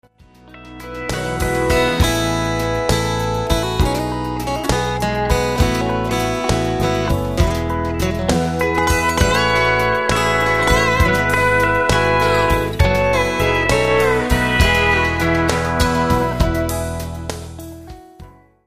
Hier im semiprof - Studio entstehen z.B. Demo-Produktionen, Rundfunkspots, Jingles, Halbplaybacks, Theatermusik... - einige Beispiel-Ausschnitte gibt's als MP3_files
Neben 'richtigen' Instrumenten gibt's diverse MIDI-Klangerzeuger, NEUMANN TLM 170 Micros, TLA Compressor, YAMAHA O2R-Mixer u.s.w.
Bsp Chorus Grandpa